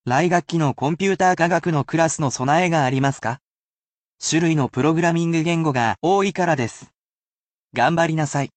However, I do not only give you useful definitions, romanisation, hiragana, and kanji, but I also give the pronunciation for you to listen to as many times as you wish.
As a bilingual computer, I can relate to this topic.